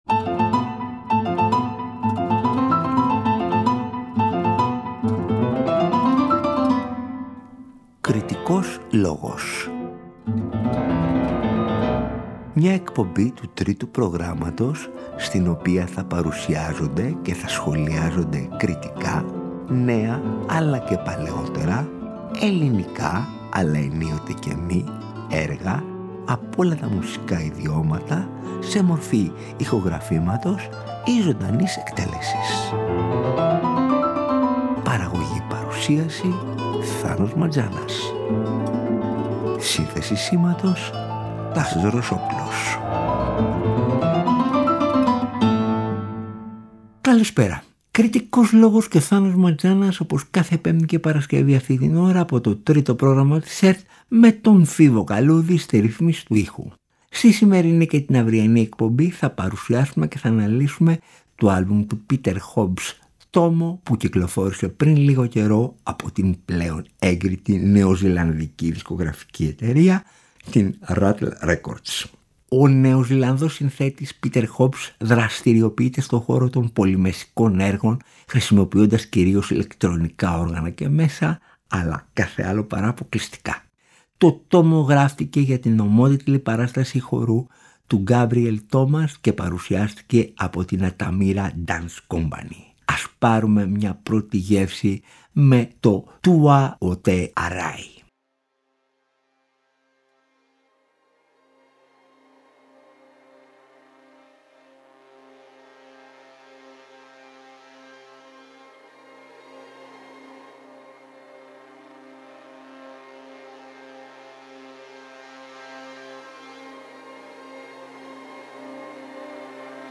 Το έργο κινείται στον ευρύτατο χώρο της ambient με έμφαση στην πιο dark πλευρά της. Για την ηχογράφηση του χρησιμοποιήθηκαν modular synthesizers, ντραμς, τρομπέτα, η οκαρίνα των Μαορί που ονομάζεται p ̄ut ̄orino και βιολοντσέλο καθώς και ήχοι περιβάλλοντος από τα σπήλαια της περιοχής Waitakere όπου ο Peter Hobbs συνέθεσε το έργο.